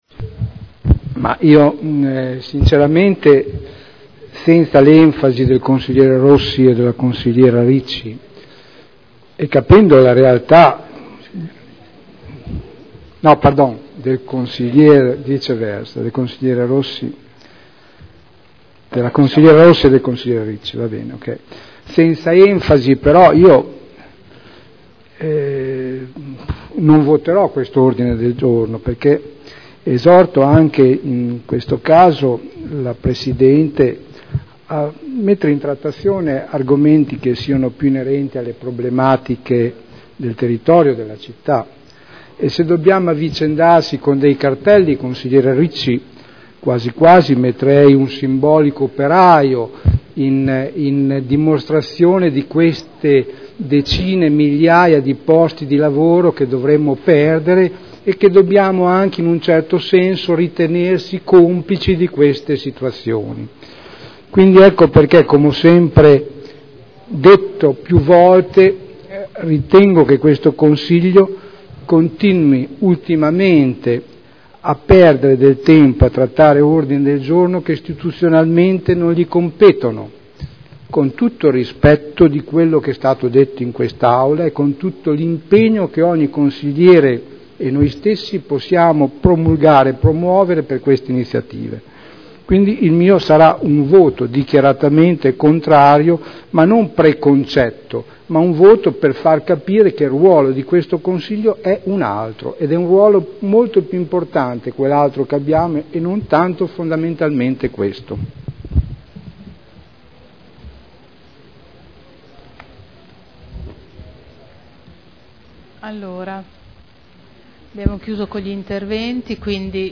Sergio Celloni — Sito Audio Consiglio Comunale